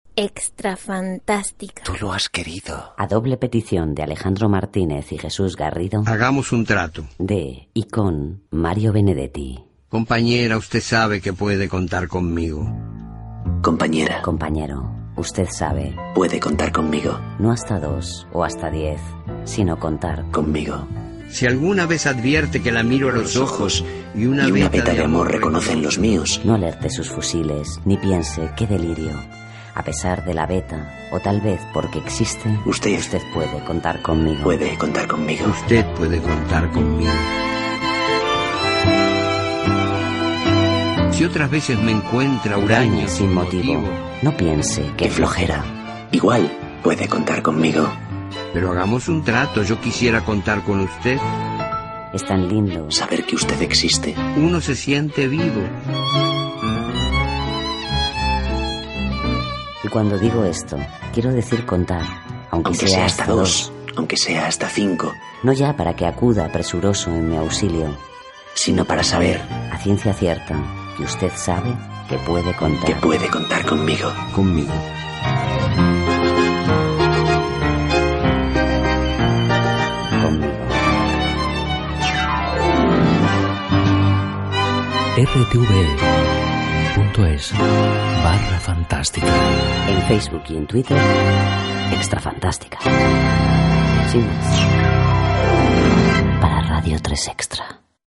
Cultura